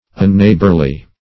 Unneighborly \Un*neigh"bor*ly\, a.